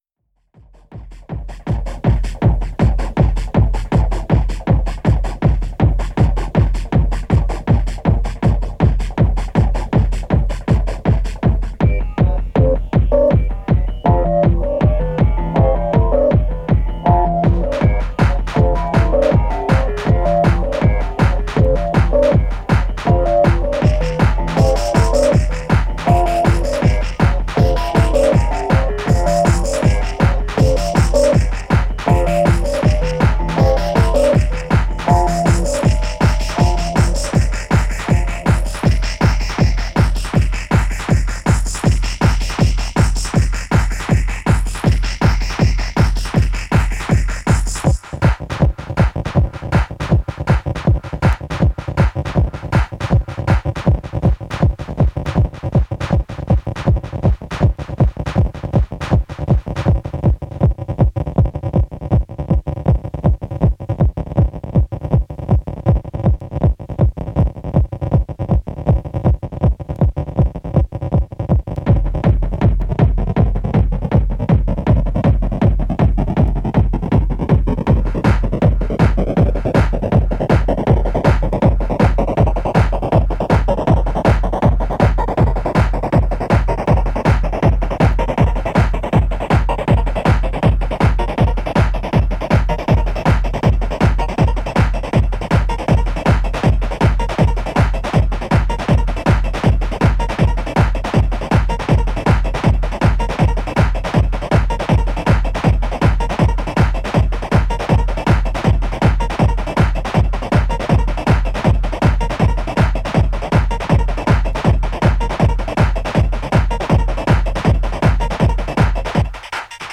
( 160bpm )